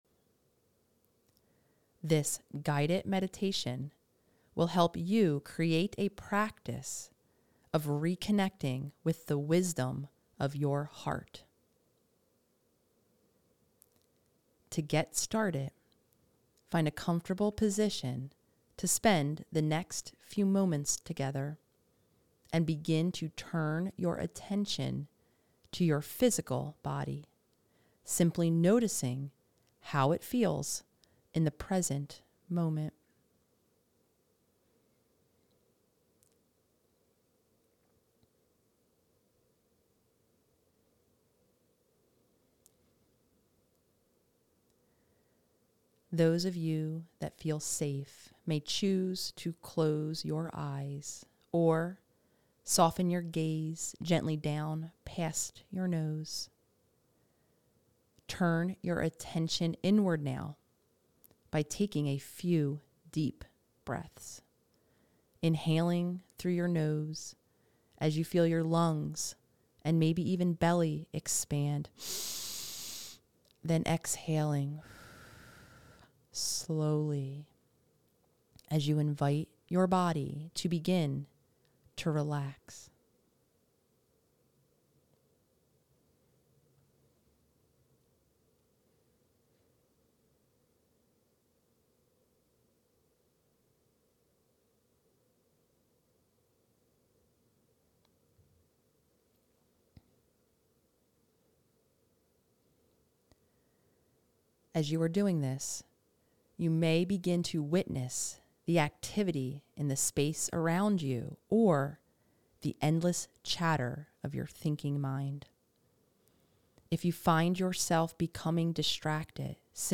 Connect-With-Your-Heart-Guided-Meditation.mp3